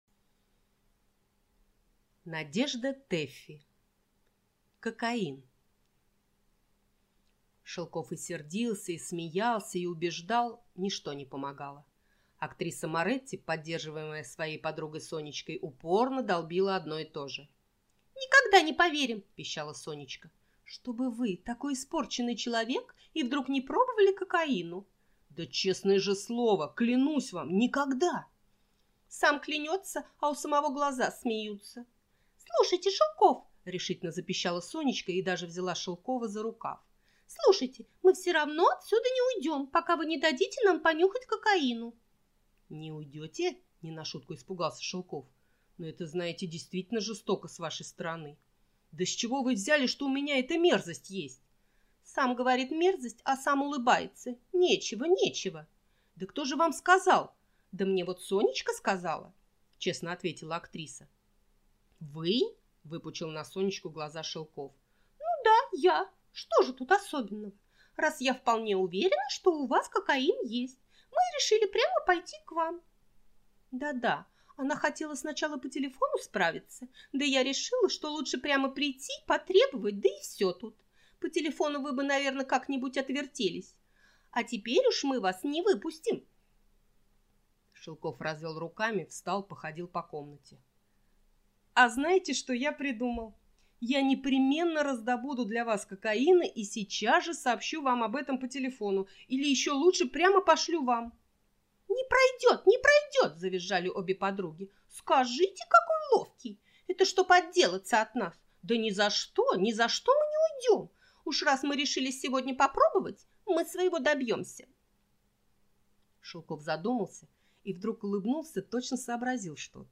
Аудиокнига Кокаин | Библиотека аудиокниг